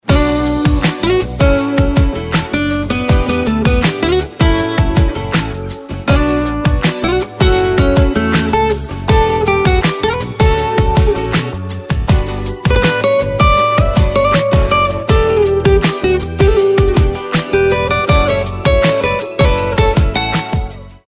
رینگتون تلفن همراه ملایم